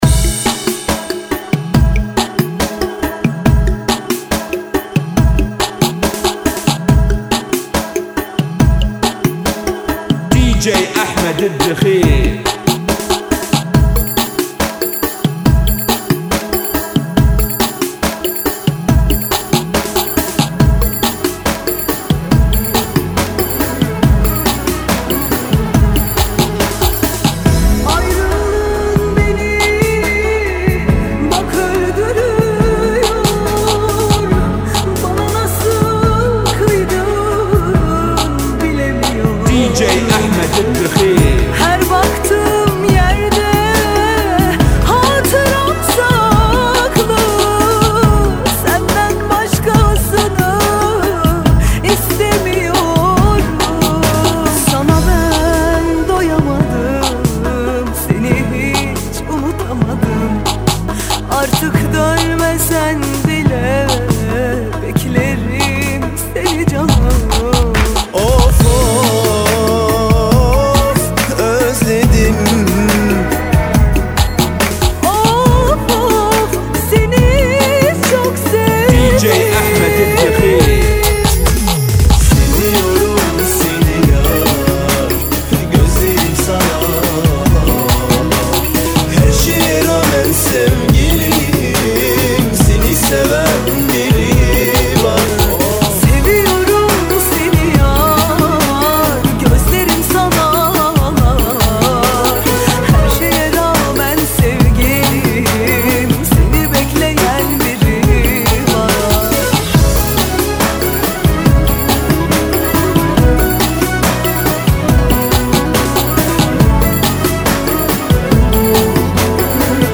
اغنيه تركيه - ريمكس